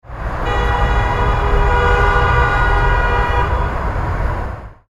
Cars Passing With Horns Through City Crowd Noise Sound Effect
Description: Cars passing with horns through city crowd noise sound effect. Experience cars driving past with loud horns in city noise.
Cars-passing-with-horns-through-city-crowd-noise-sound-effect.mp3